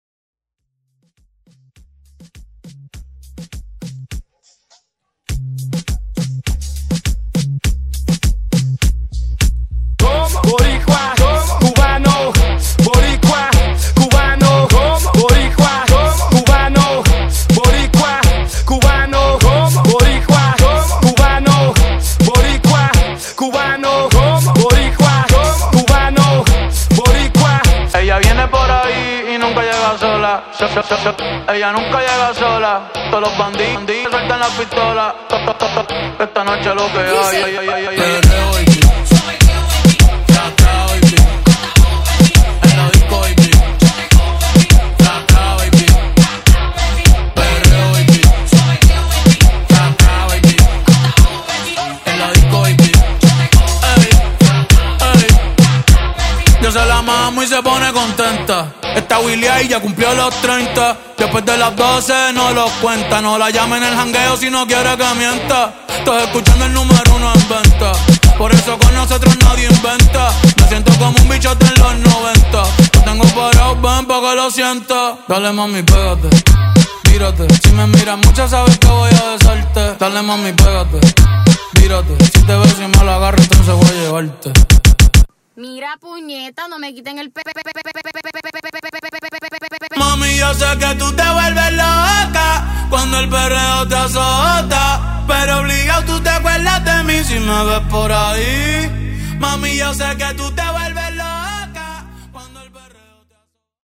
Genre: FUTURE HOUSE Version: Clean BPM: 128 Time